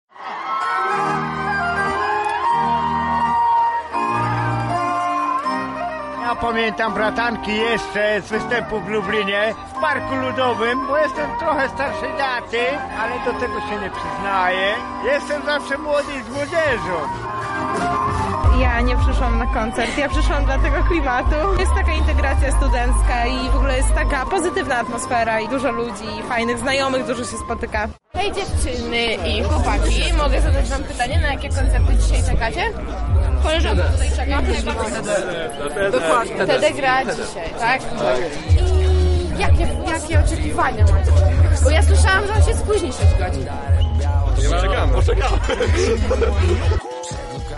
Tak o swoich wrażeniach opowiedzieli uczestnicy studenckiej imprezy